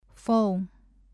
2. 両唇摩擦音 bilabial fricative
ဖုန်း [fóuɴ~pʰóuɴ ]電話 ＜ Eng. phone